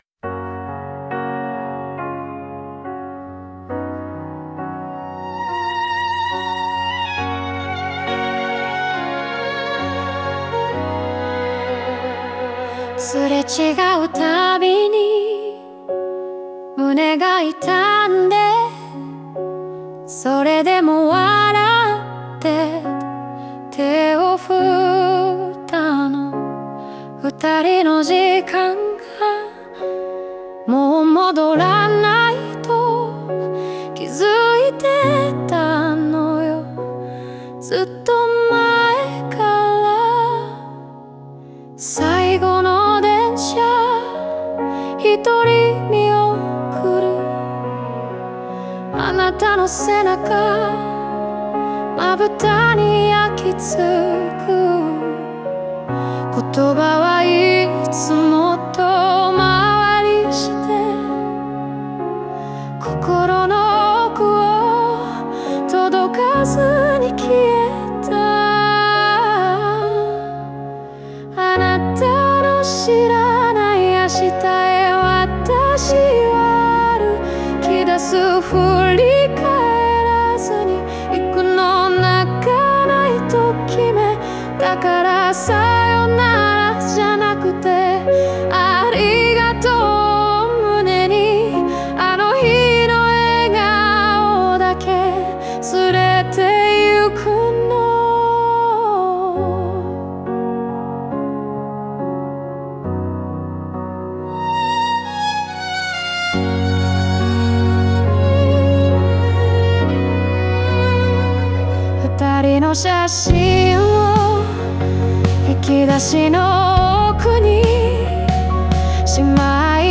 過去への未練や感謝、そして前に進む決意が、穏やかな旋律と共に語られる構成です。